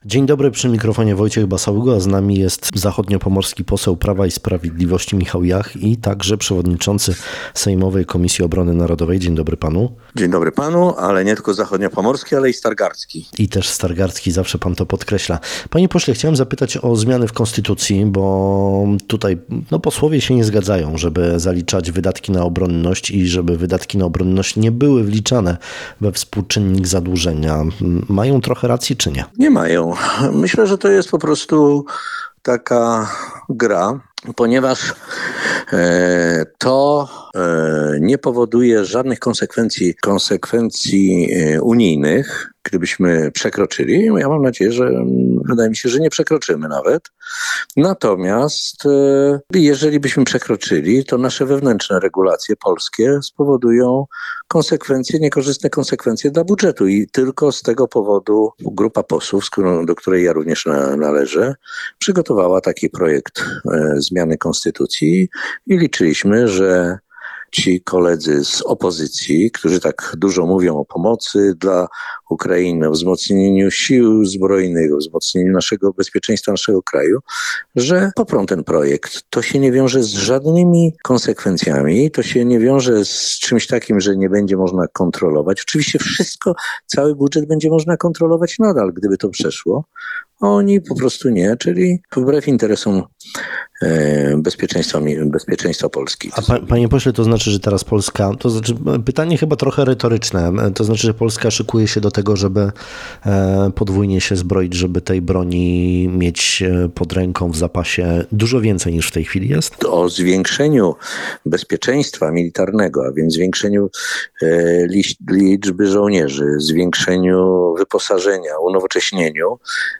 Między innymi właśnie o to zapytaliśmy naszego gościa dzisiejszej Rozmowy Dnia – Michała Jacha, zachodniopomorskiego posła Prawa i Sprawiedliwości, a jednocześnie przewodniczącego Sejmowej Komisji Obrony Narodowej.